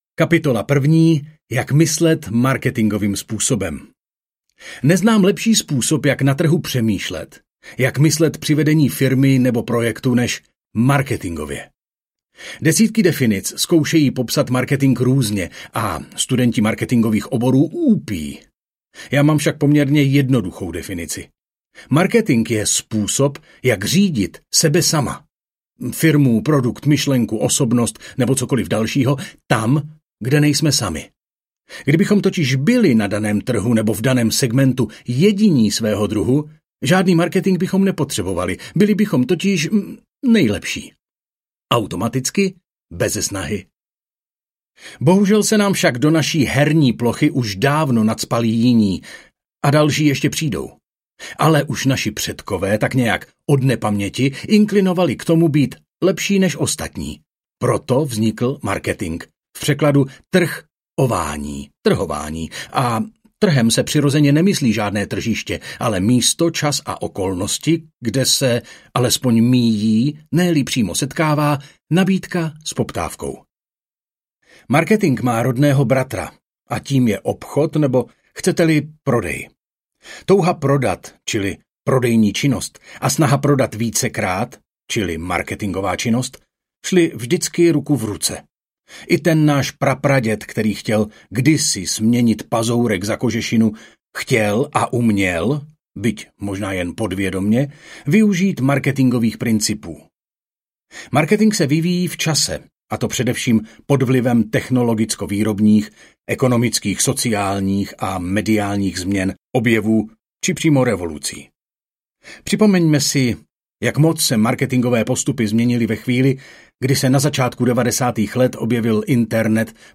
Sami v moři konkurence audiokniha
Ukázka z knihy